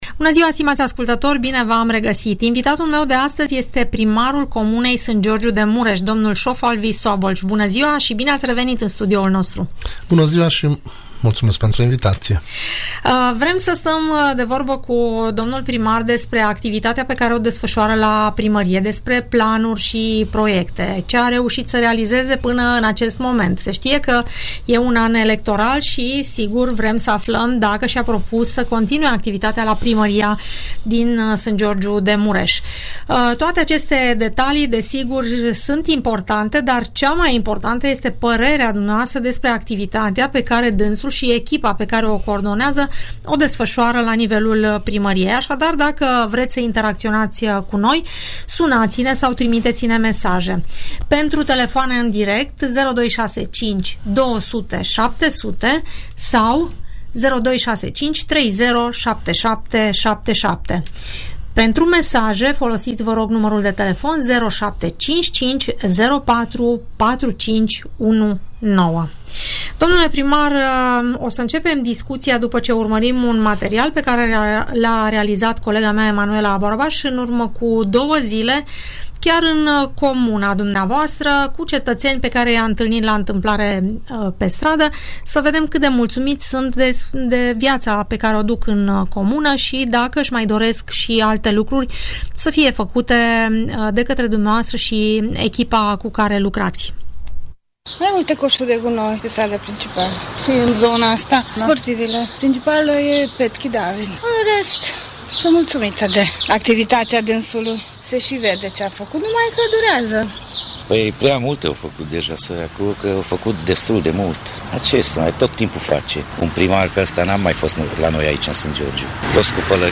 Primarul comunei Sângeorgiu de Mureș, dl. Sófalvi Szabolcs, vorbește la Radio Tg. Mureș despre investițiile în derulare dar și despre cele care vor duce la dezvoltarea localității în viitor.
Primarul din Sângeorgiu de Mureș, în dialog cu cetățenii